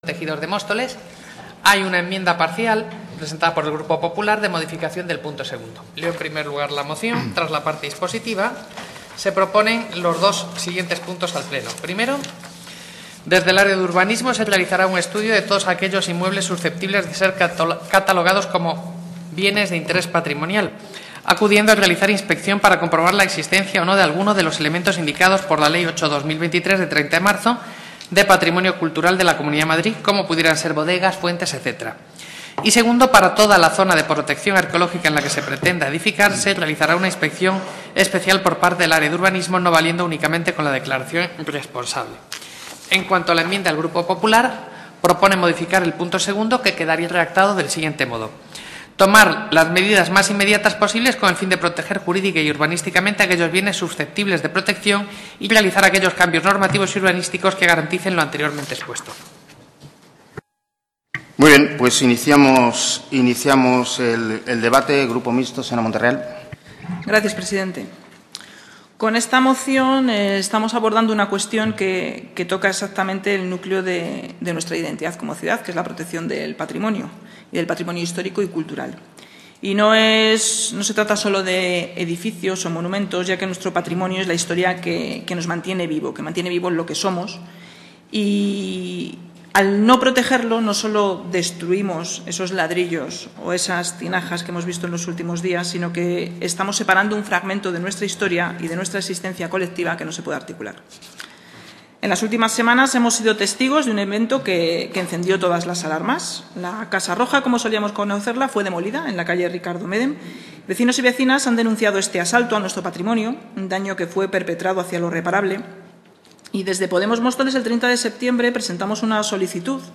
Pleno Ayuntamiento. Aprobación bienes culturales